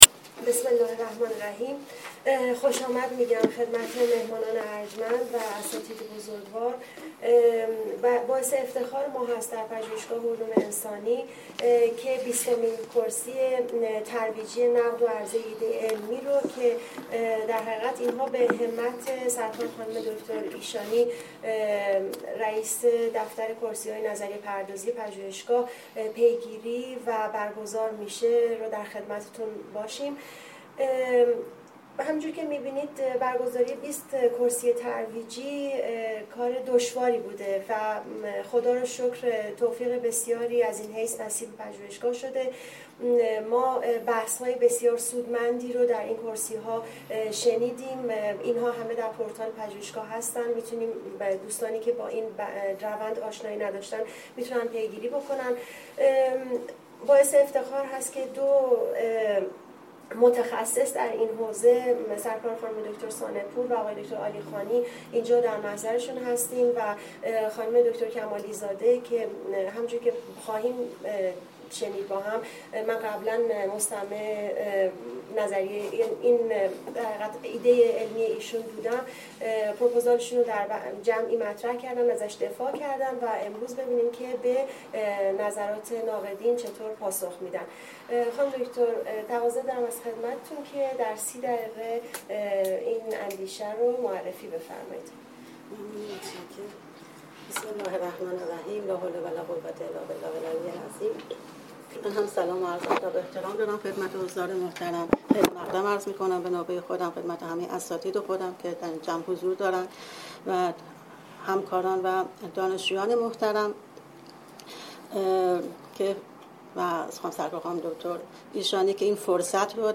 بیستمین کرسی ترویجی با رویکرد «عرضه و نقد ایده علمی» برگزار میکند: عنوان: بررسی امکان ارائه ایده فلسفه هنر ایرانی اسلامی براساس آرای فلسفی سهروردی
زمان و مکان برگزاری: دوشنبه ۱۱/۱۰/۹۶ ساعت ۱۲:۰۰-۱۰:۰۰ سالن اندیشه